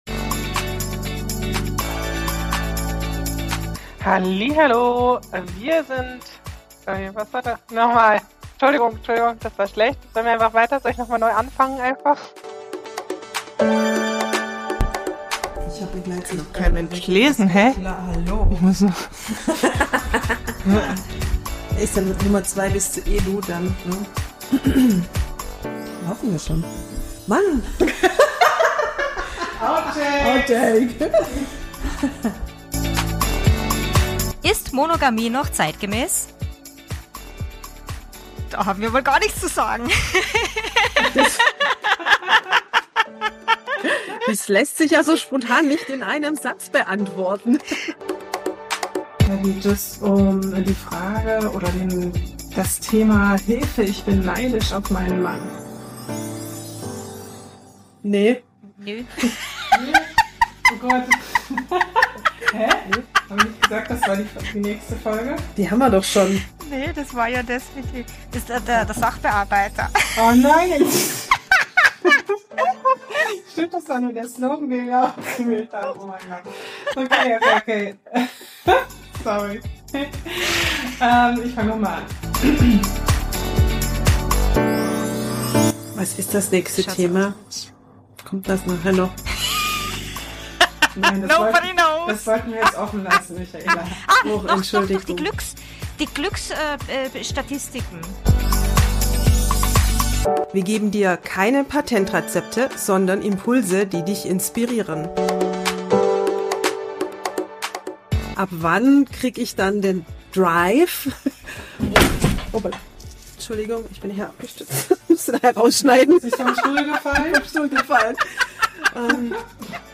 Outtakes 2025
Herzgespinste: Versprecher, Lachanfälle, Patzer
ehrlich, ungefiltert und ziemlich chaotisch.